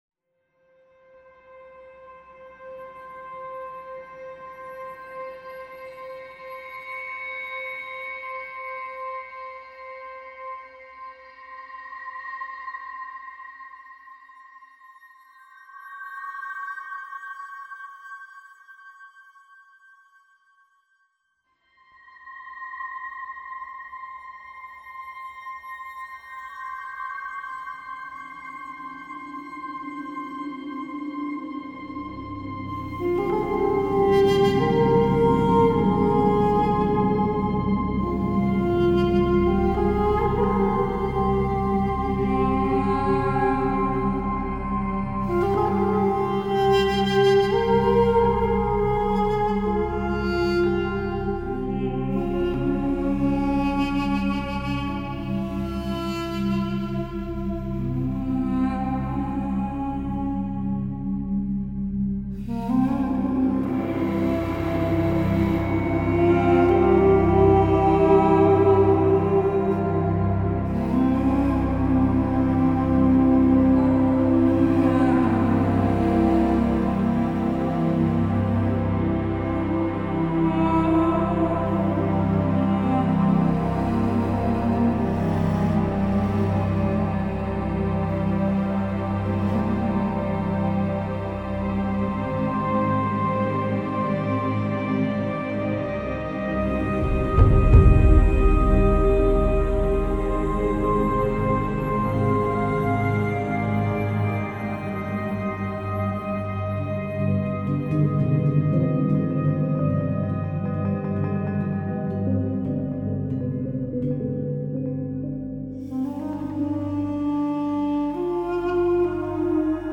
Друзья, подскажите как называется солирующий инструмент с 0:33 :)